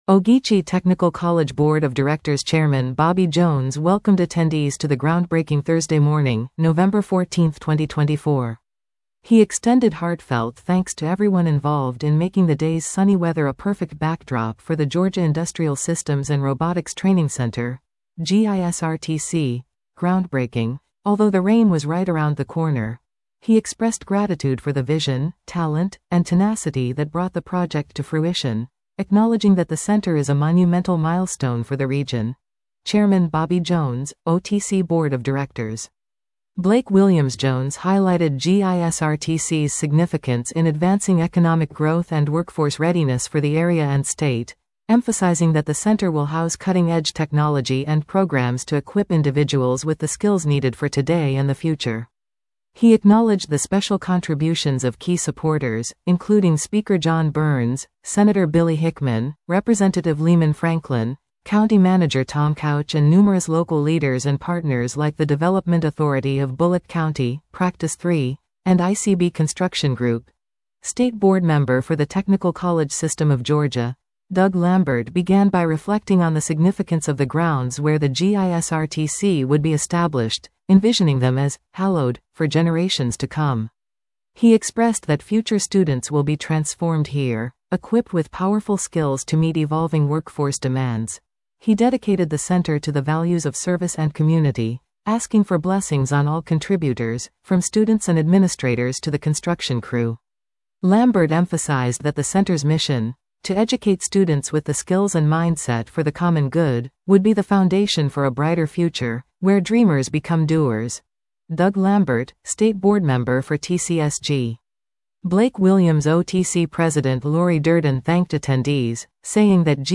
The Georgia Industrial Systems & Robotics Training Center (GISRTC) groundbreaking celebrated a partnership between state leaders and Ogeechee Technical College to boost workforce skills in robotics and industrial systems. This new facility aims to prepare Georgians for high-demand jobs, supporting economic growth across the region.